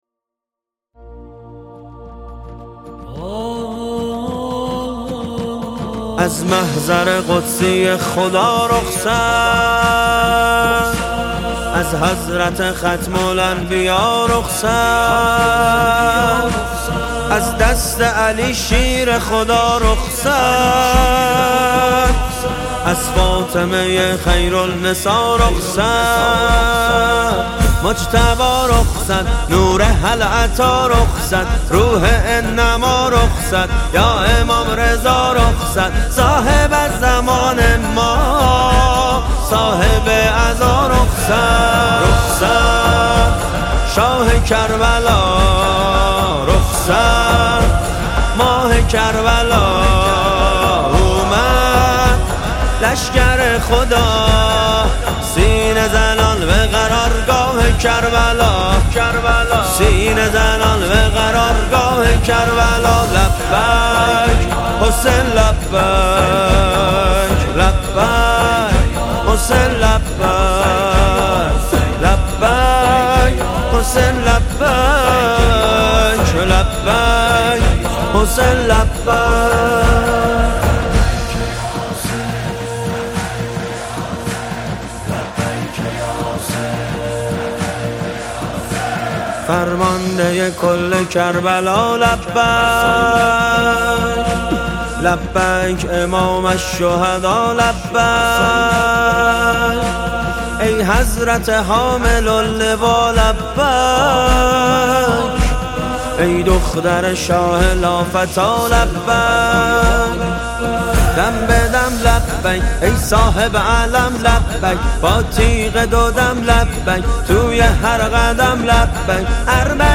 دانلود نماهنگ دلنشین